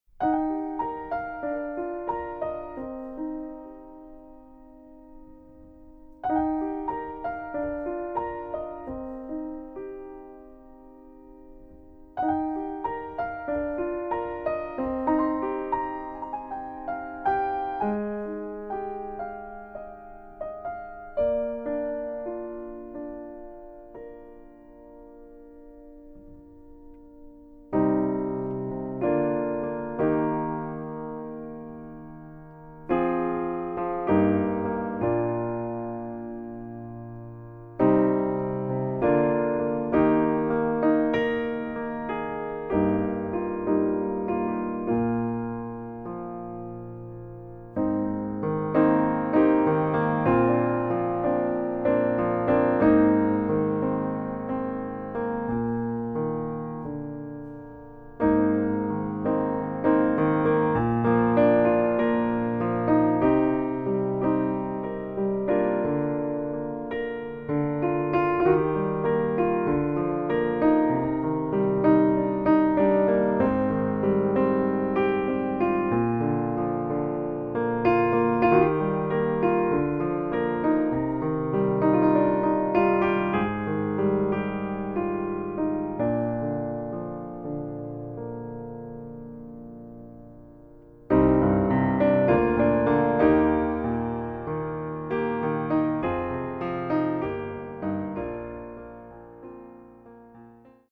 Level : Intermediate | Key : E-flat | Individual PDF : $3.99